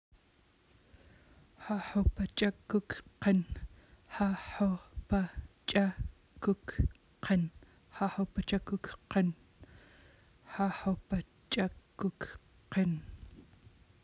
pronunciation]